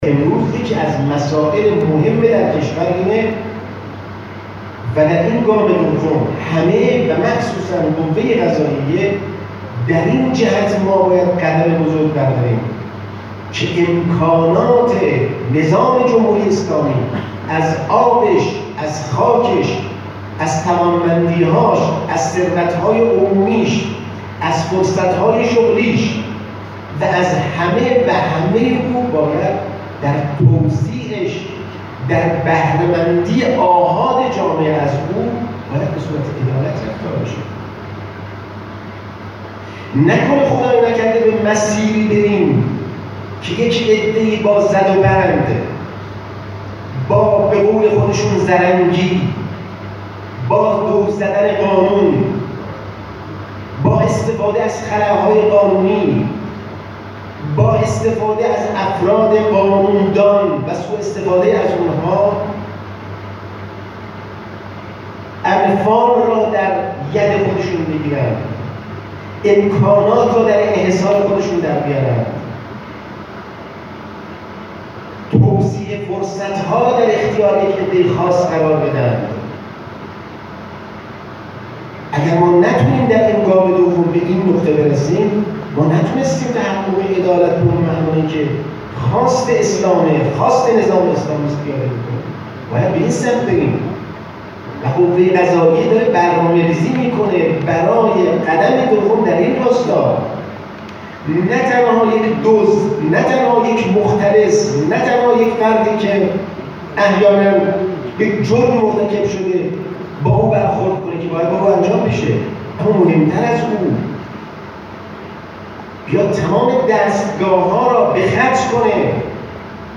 به گزارش خبرنگار خبرگزاری رسا در خراسان جنوبی، حجت الاسلام غلامحسین محسنی اژه‌‌ای، معاون اول قوه قضاییه ظهر امروز در جمع خبرنگاران اظهار داشت: به طور نسبی وضعیت رسیدگی به پرونده‌های استان خوب است اما باید بهتر شود.